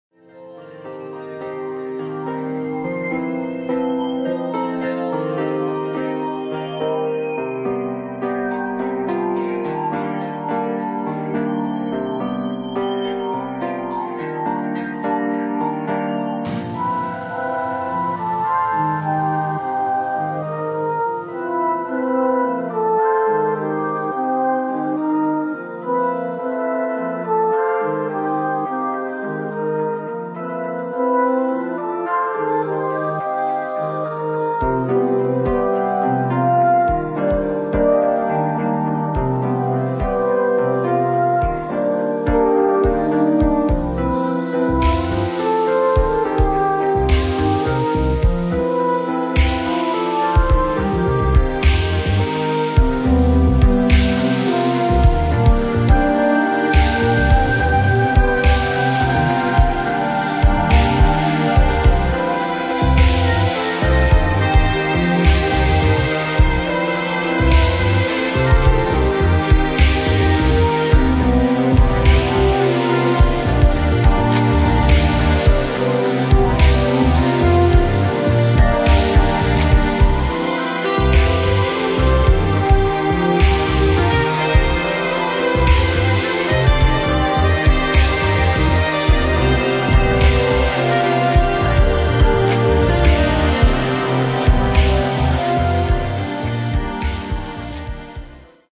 [ HOUSE / ELECTRONIC ]